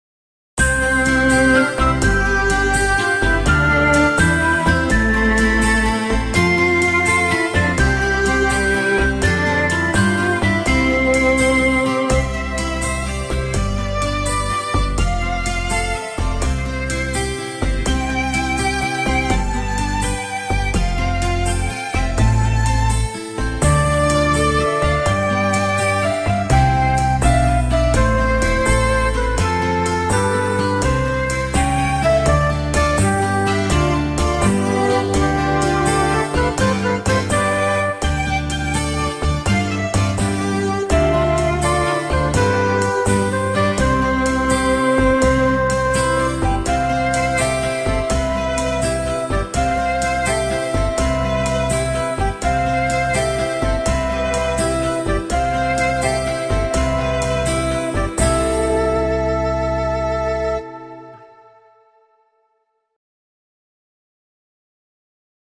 Колыбельная